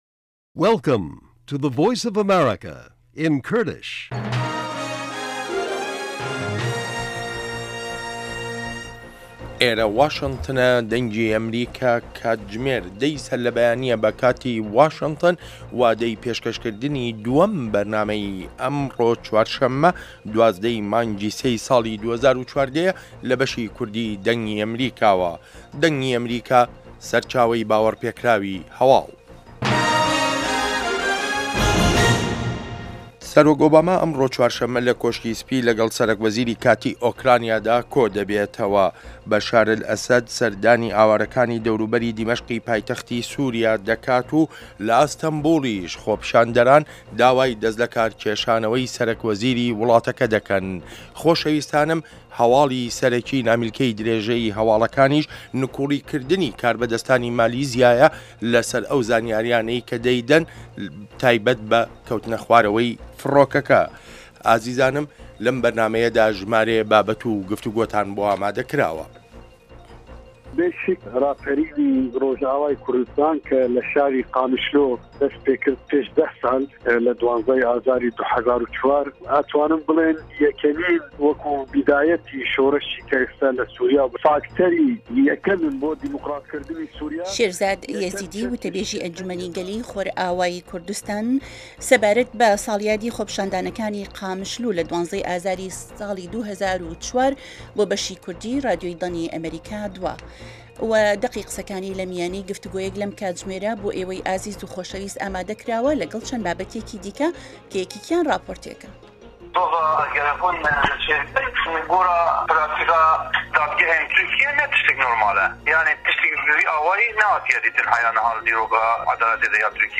بابه‌ته‌كانی ئه‌م كاتژمێره بریتین له هه‌واڵه‌كان، ڕاپۆرتی په‌یامنێران، گفتوگۆ و شیكردنه‌وه، ئه‌مڕۆ له مێژووی ئه‌مه‌ریكادا، هه‌روههاش بابهتی ههمهجۆری هونهری، زانستی و تهندروستی، ئابوری، گهشتێك به نێو ڕۆژنامه جیهانییهكاندا، دیدوبۆچونی واشنتۆن، گۆرانی كوردی و ئهمهریكی و بهرنامهی ئهستێره گهشهكان له ڕۆژانی ههینیدا.